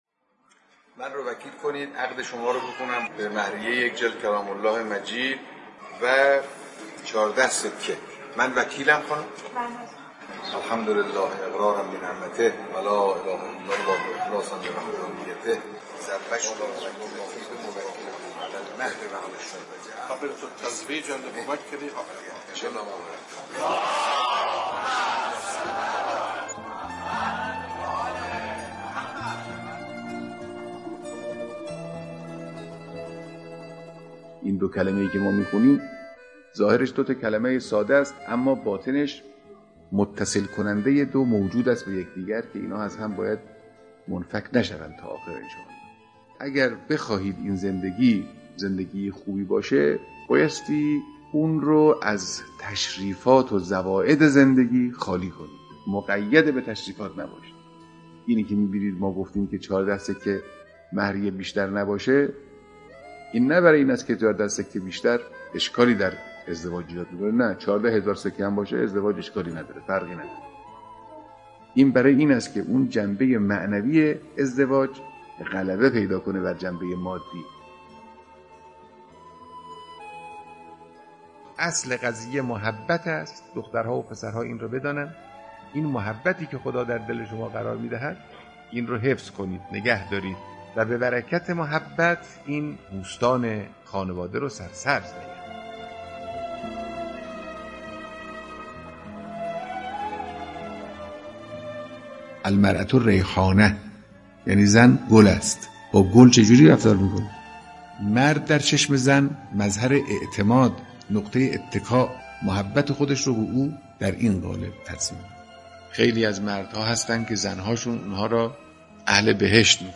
صوت کامل بیانات